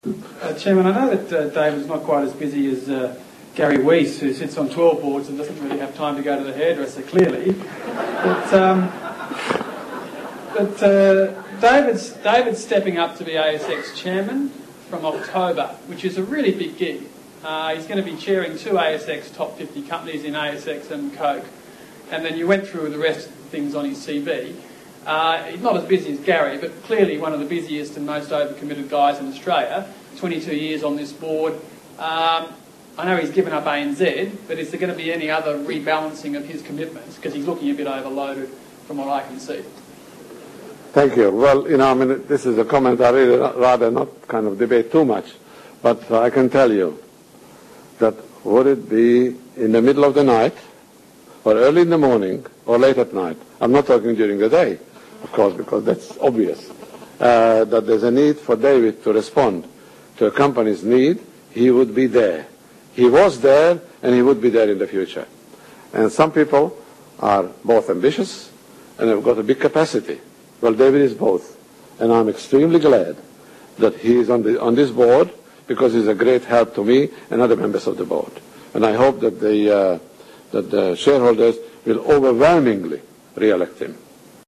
After having lots of fun at Macquarie Airports yesterday it's hard to believe that today's Westfield AGM was even better.